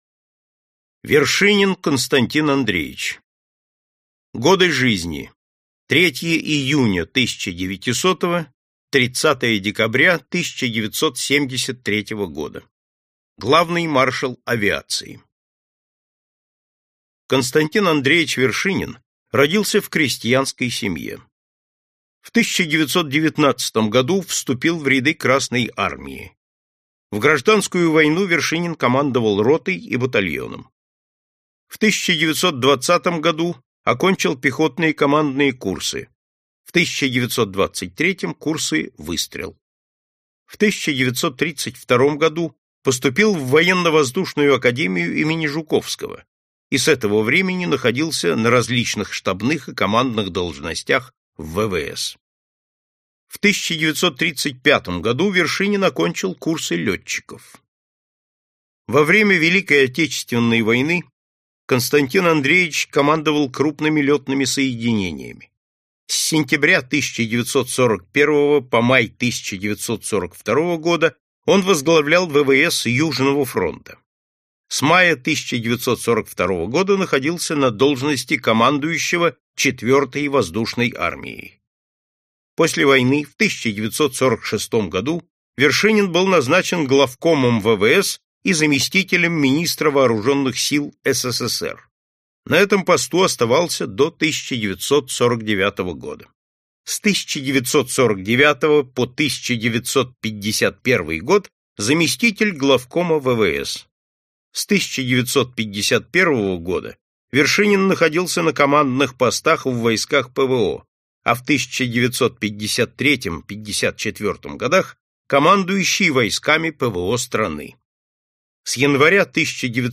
Аудиокнига Великие полководцы второй мировой войны | Библиотека аудиокниг